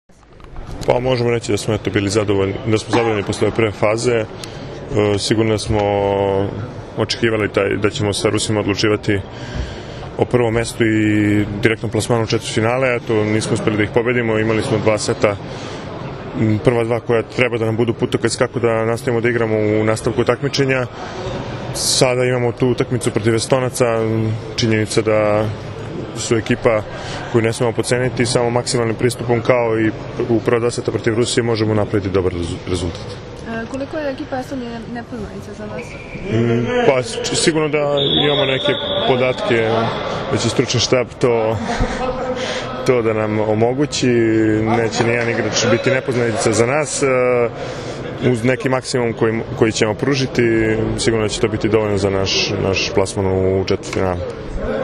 IZJAVA NEVENA MAJSTOROVIĆA